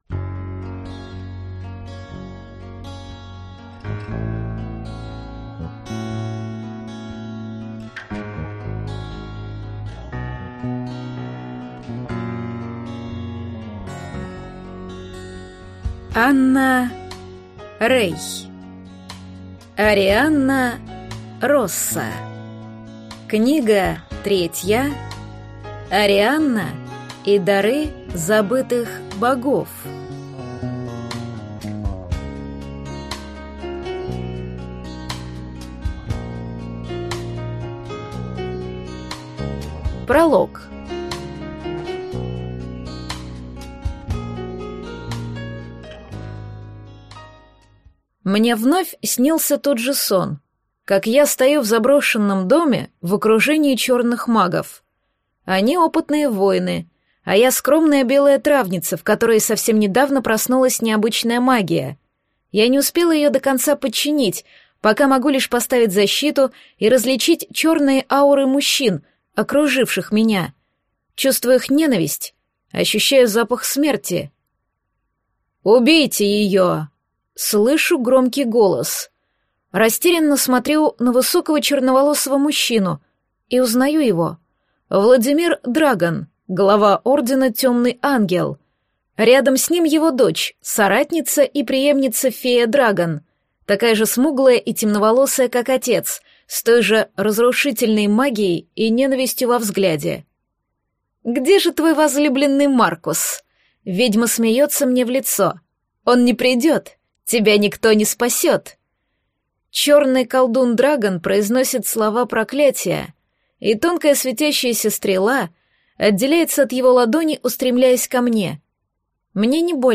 Аудиокнига Арианна и дары забытых богов | Библиотека аудиокниг